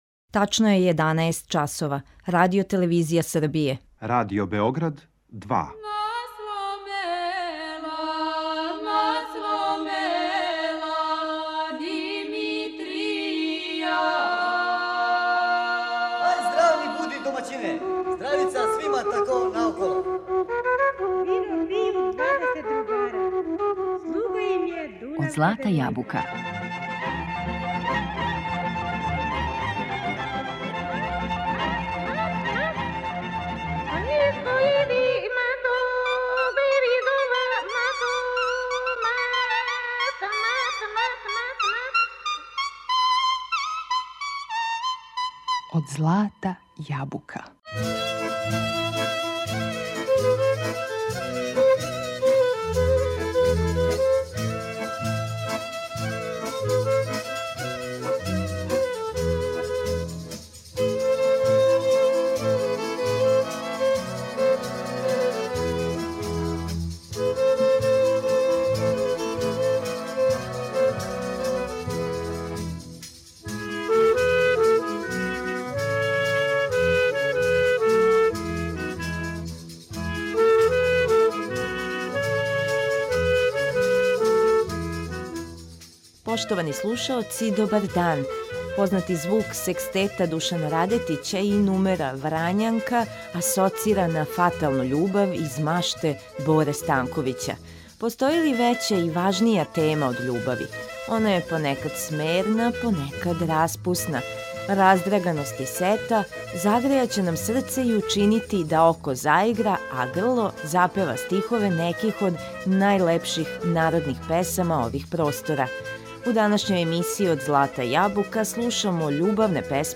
Раздраганост и сета истовремено, управо су оно што ће учинити да нам око заигра, уз најлепше мелодије ових простора. У данашњем издању емисије Од злата јабука на репертоару су најлепше песме са Балкана.